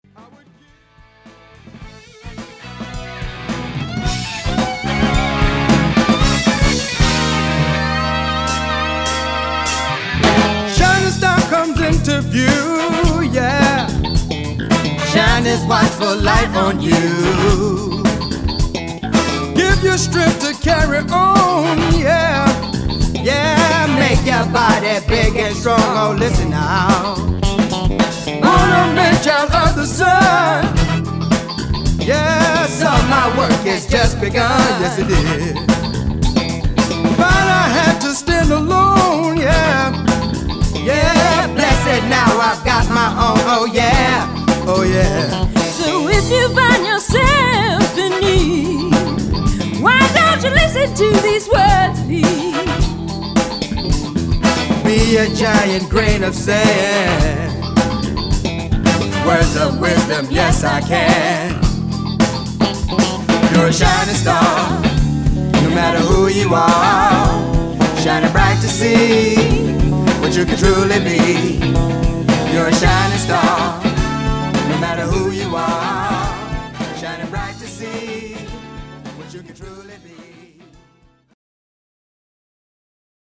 classic soul and funk music from the 60s and 70s